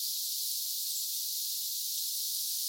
tuollainen tiltalttilinnun ääni
tuollainen_tiltalttilinnun_aani.mp3